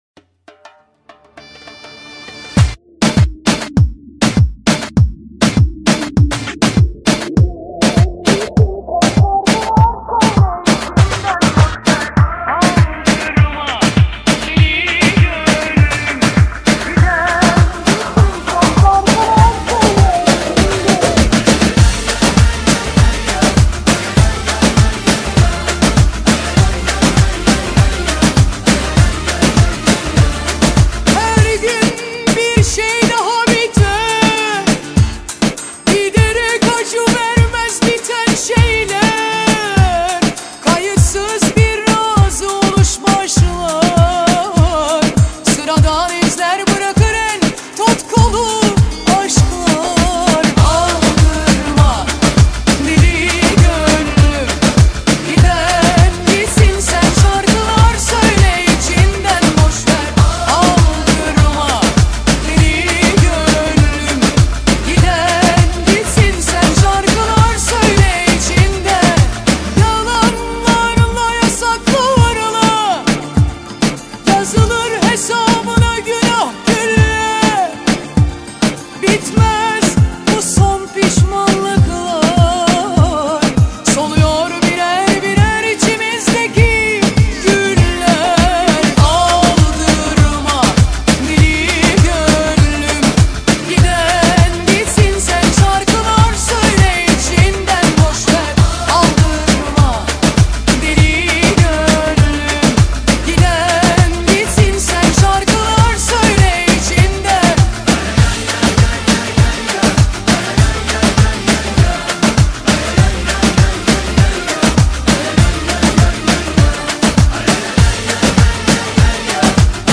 خواننده زن ریمیکس